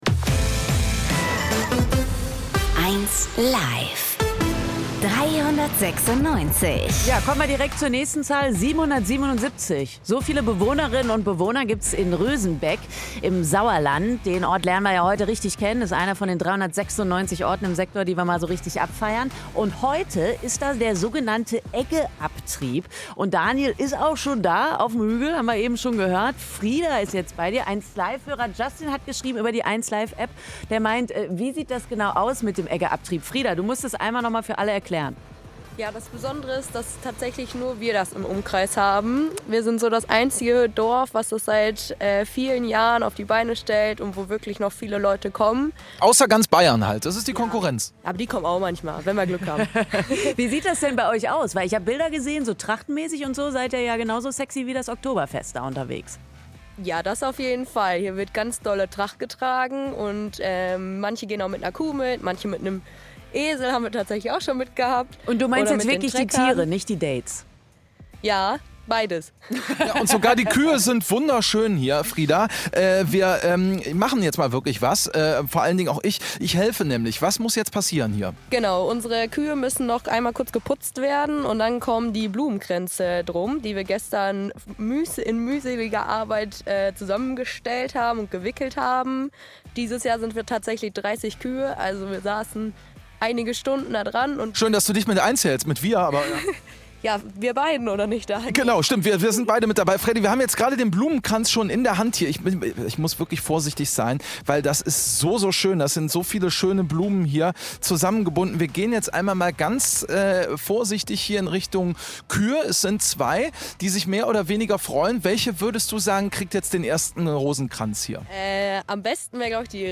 audio Schützenköniginnen, Ausblick von der Grillhütte